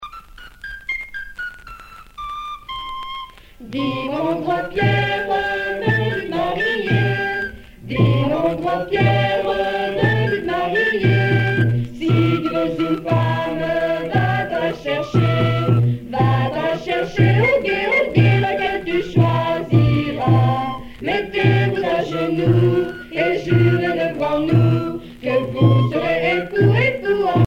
Rondes à baisers et à mariages fictifs
danse : ronde